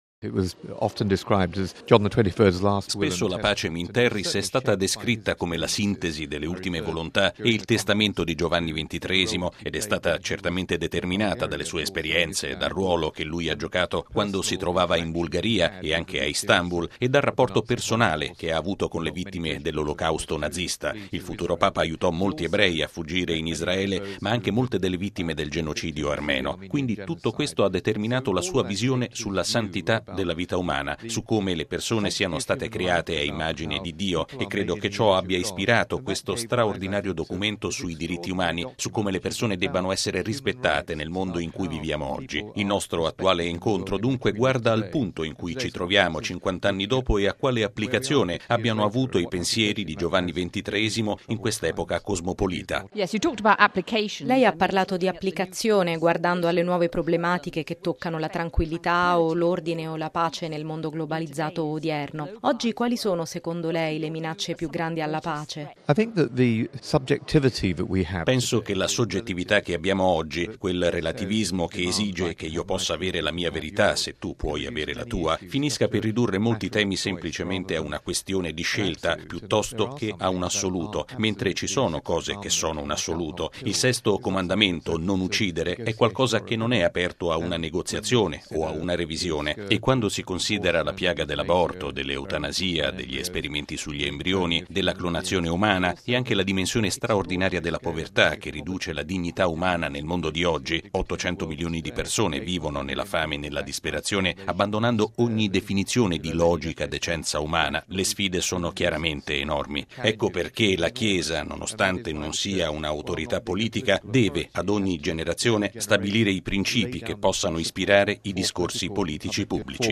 Plenaria delle Scienze Sociali sulla "Pacem in terris": commento del deputato inglese David Alton